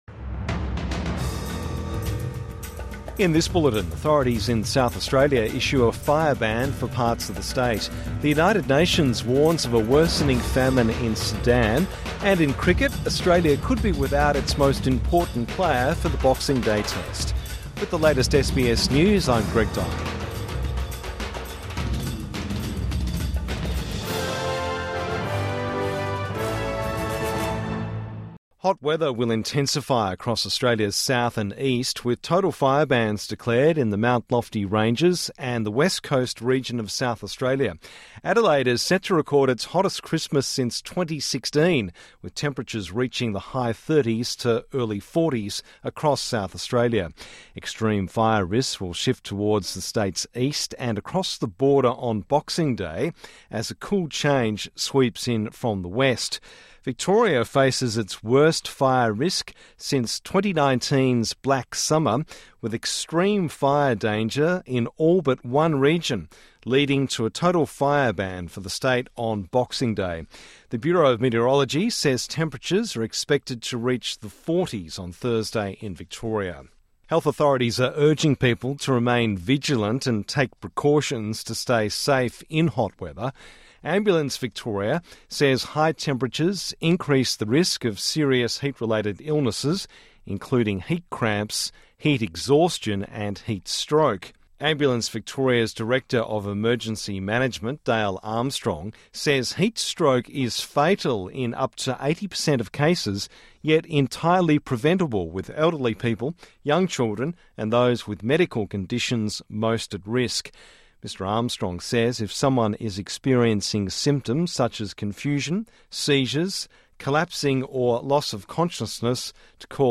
Midday News Bulletin 25 December 2024